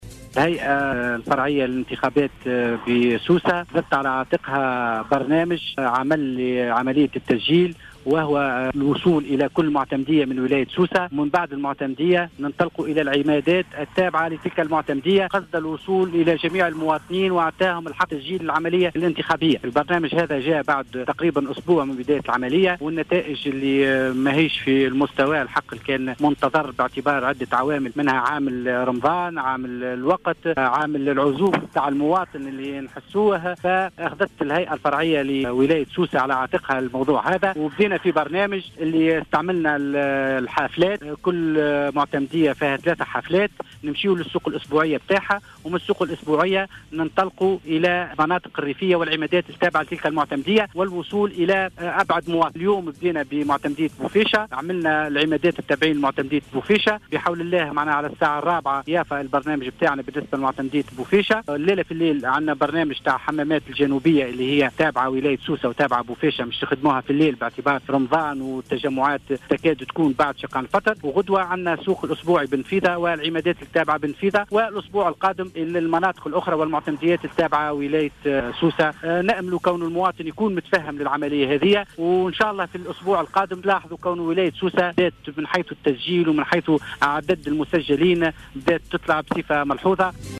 في تصريح لجوهرة أف أم